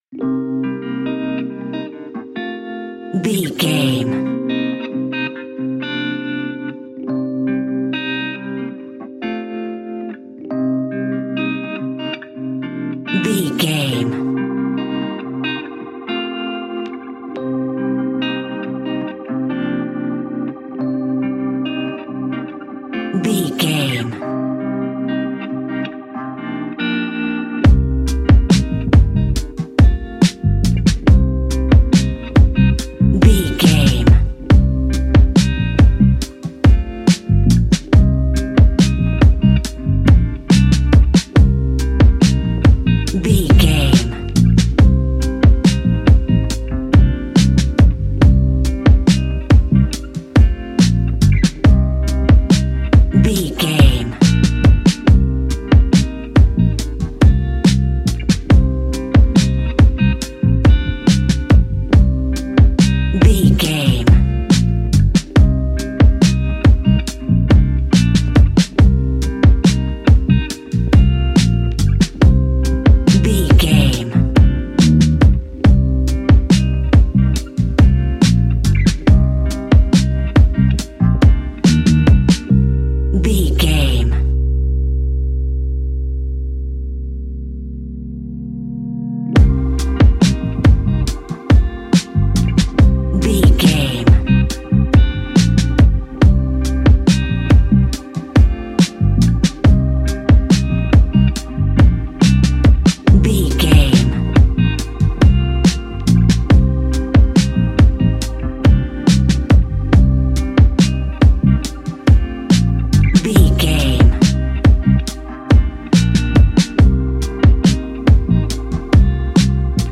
Ionian/Major
C♭
laid back
sparse
chilled electronica
ambient
atmospheric